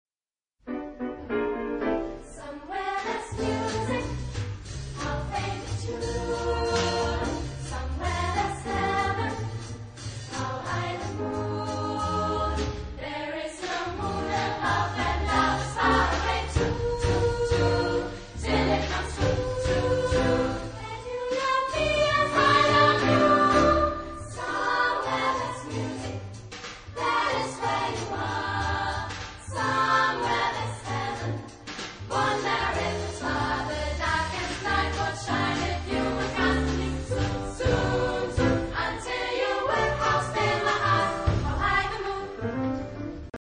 Chorgattung: SSA  (3 Frauenchor Stimmen )
Instrumente: Klavier (1)
Tonart(en): G-Dur